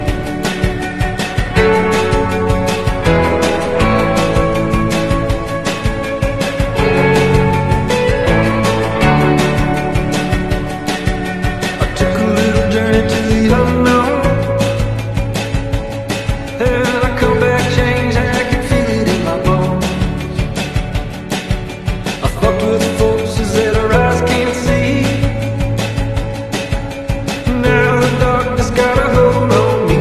Some forest serenity for your sound effects free download